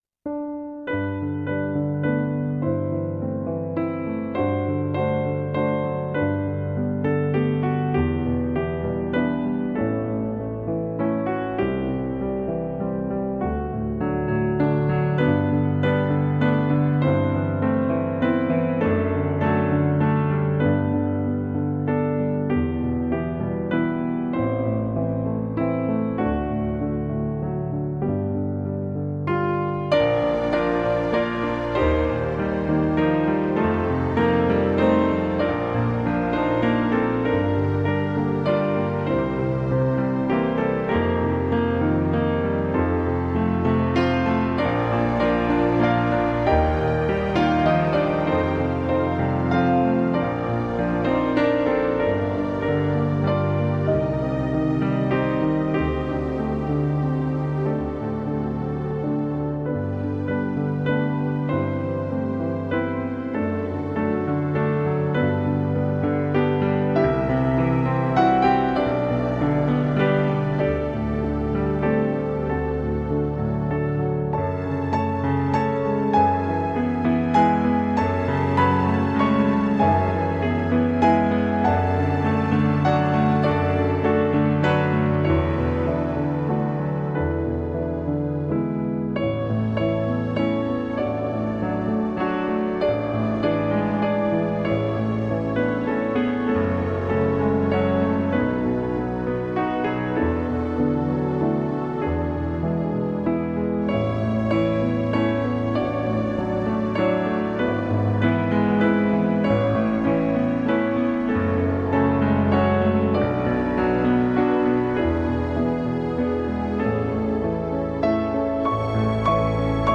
New age romantic piano music.